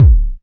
DrKick84.wav